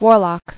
[war·lock]